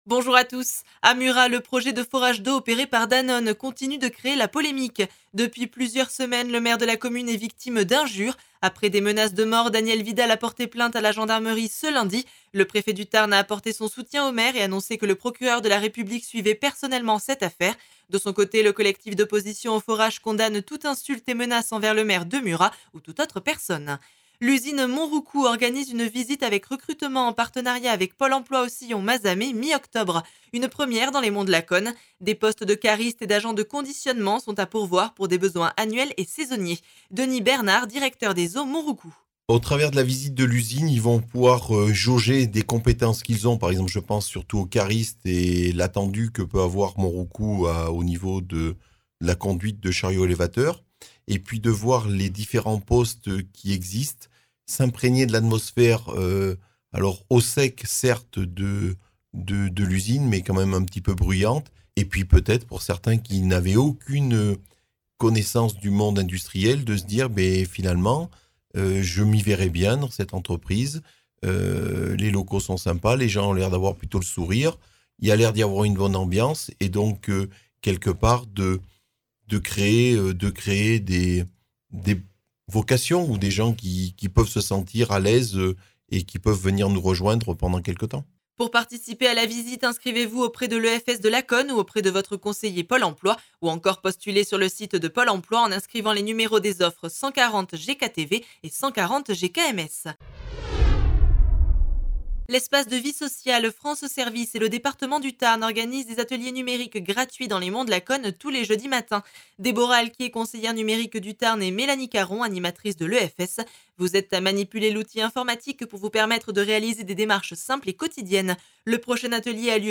Flash Monts de Lacaune 04 octobre 2022